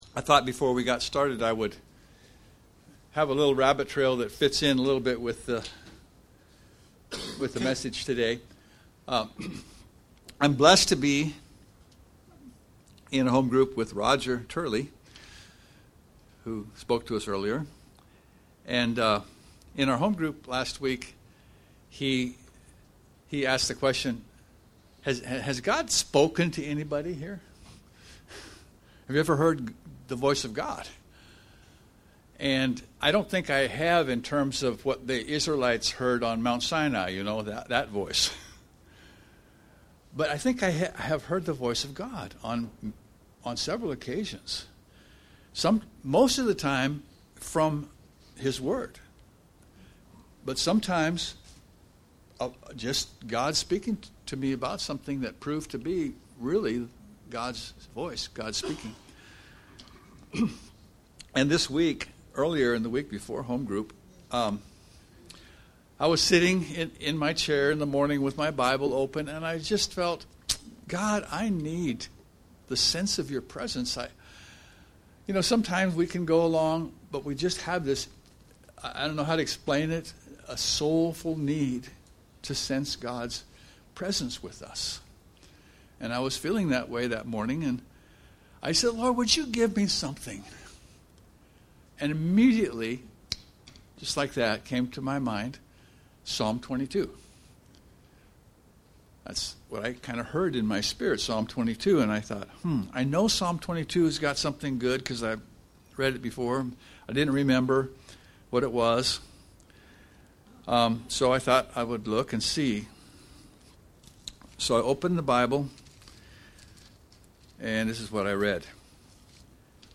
Passage: Romans 5:12-17 Service Type: Sunday Morning